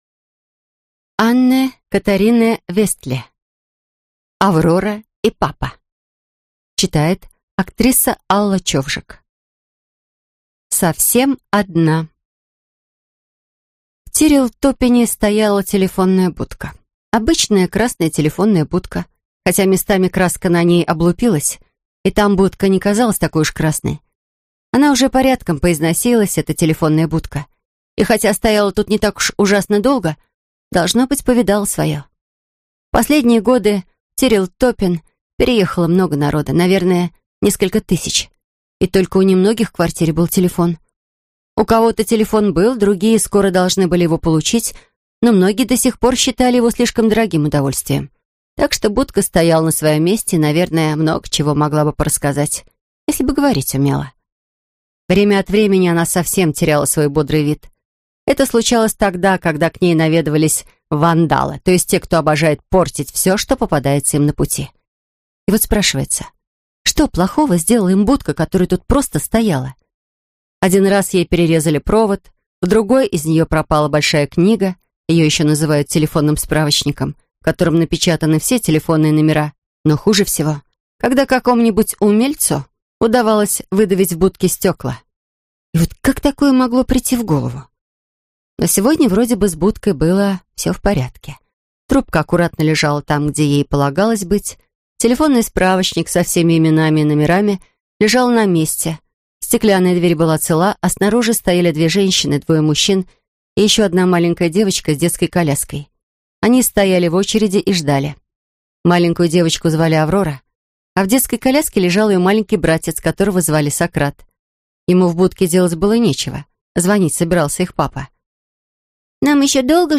Аудиокнига Аврора и папа | Библиотека аудиокниг